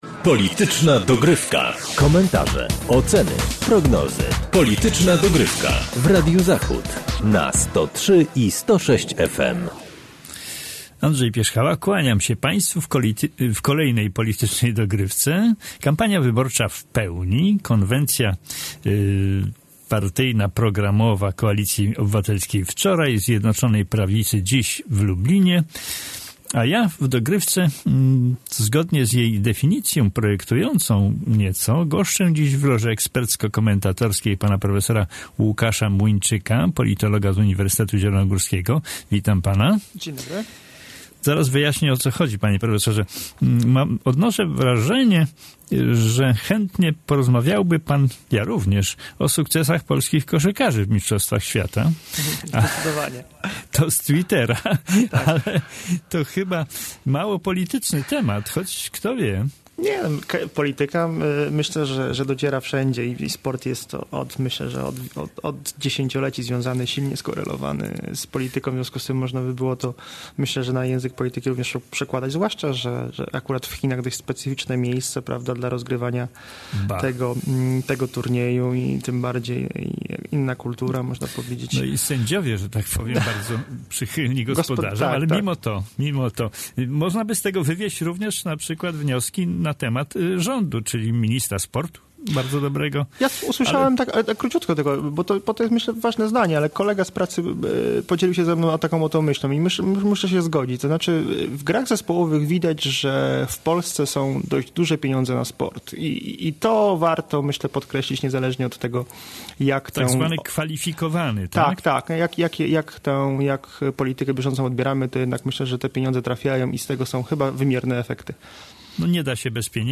Kampania wyborcza w pełni. Koalicja Obywatelska, PiS i inne ugrupowania po konwencjach. W dzisiejszej audycji rozmawiamy o kampanii wyborczej do Parlamentu.